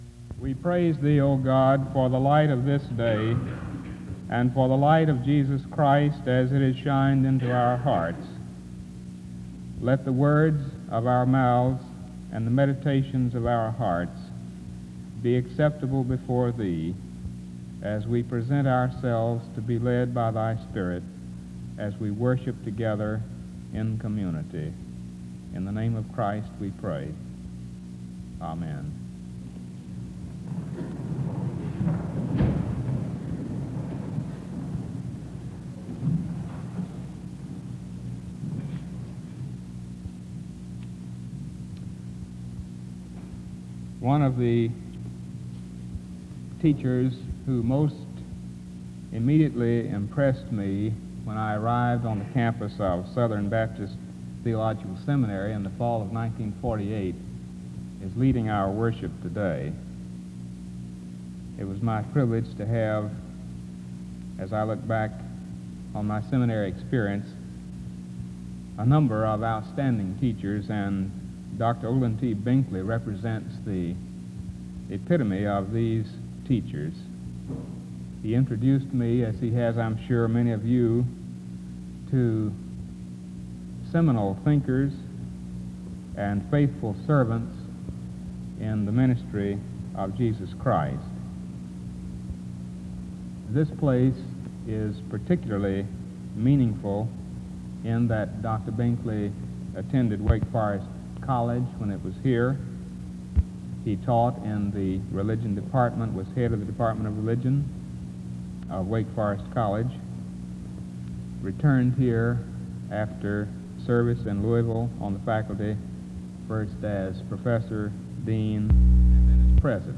The service opens with prayer from 0:00-0:29.
An introduction to the speaker is given from 0:43-2:20.
He teaches the chapel on how Christians are to think. The service closes with music from 19:46-20:01.